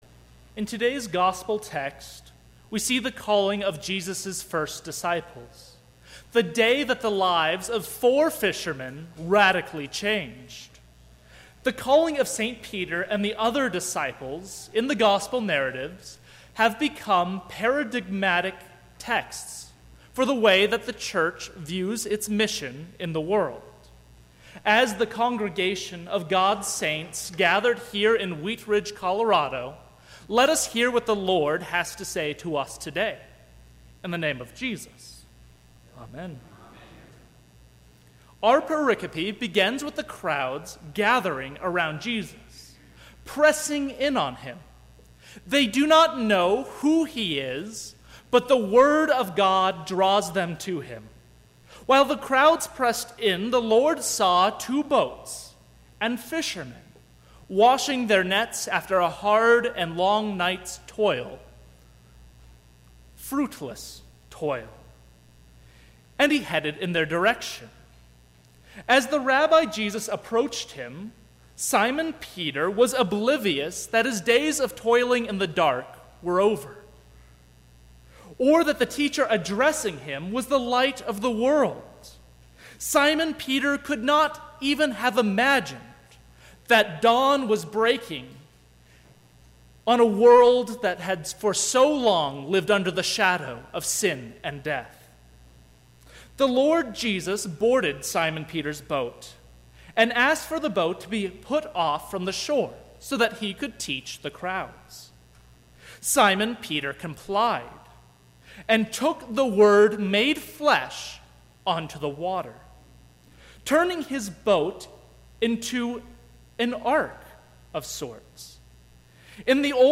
Sermon – 7/16/2017